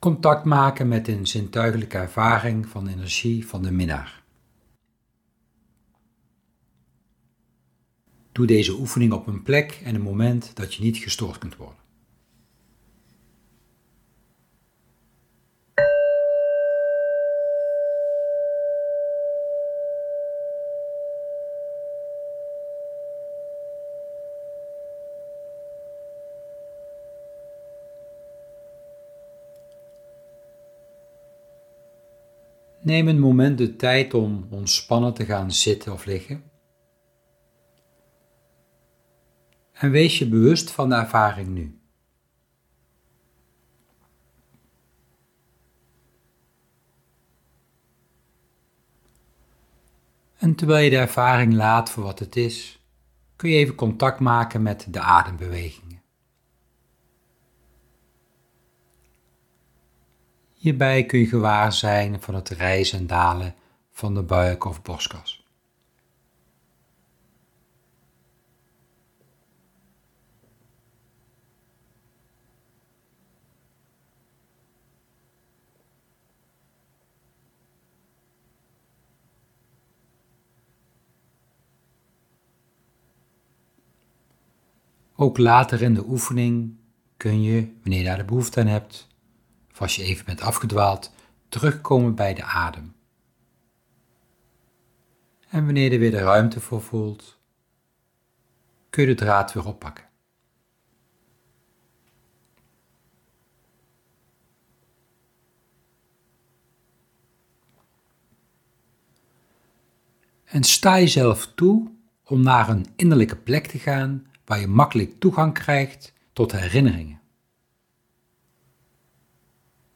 Oefening: Energie van de Minnaar ervaren